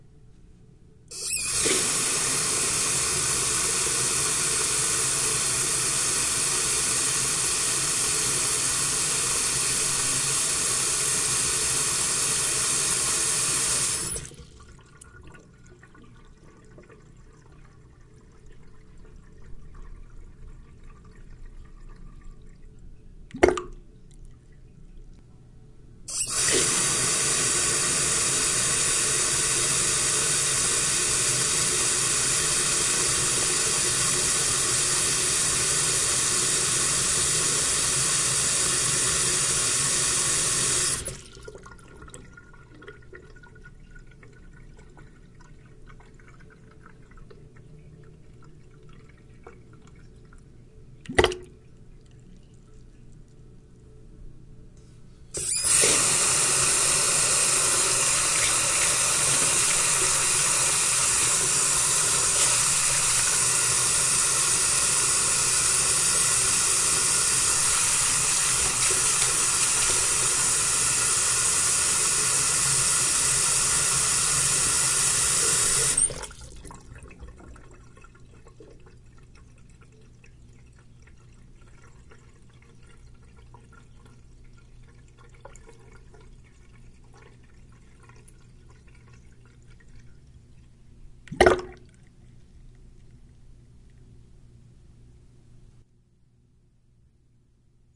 水槽
描述：标题：自来水下沉描述：小瓷器水槽，开启和关闭水，水潺潺声。
标签： 福赛特 水槽 管道
声道立体声